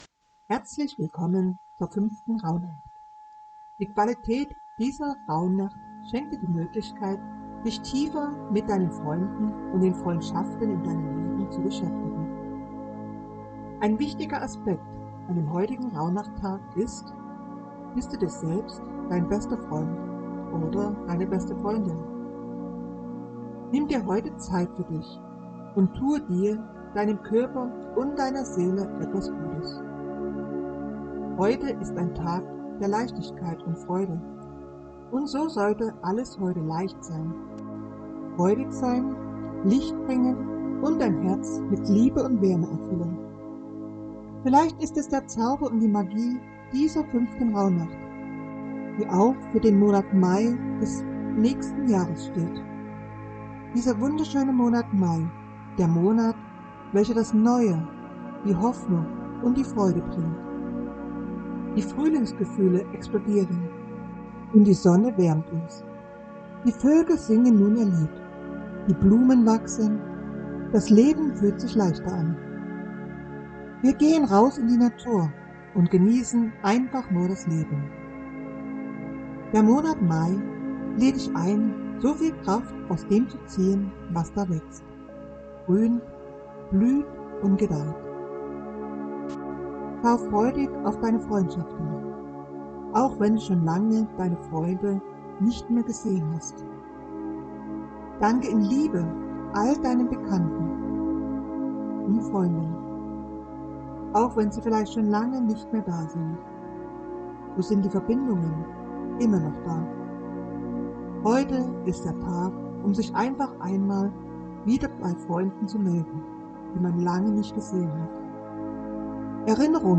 Gesprochene Worte zur fünften Rauhnacht